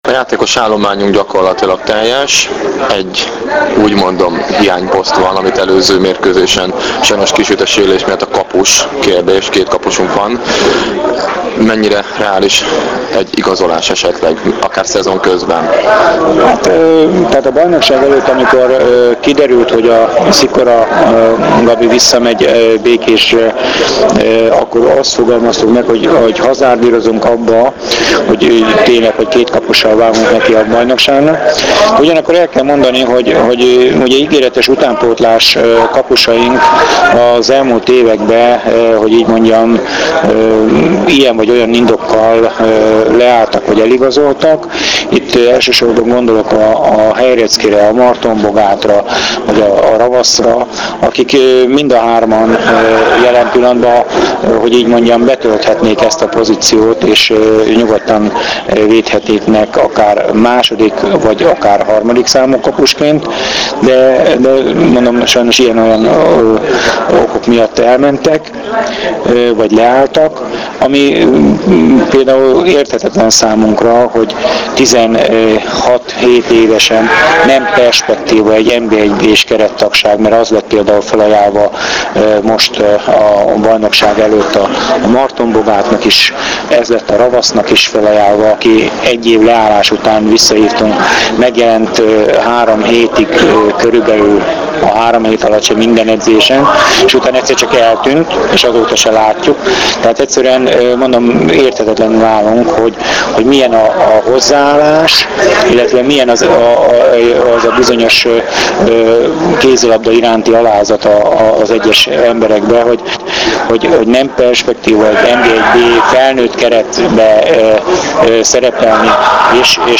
Az interjú mp3-ban >>>